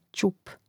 ćȕp ćup im. m. (G ćùpa, DL ćùpa, A ćȕp, I ćùpom; mn.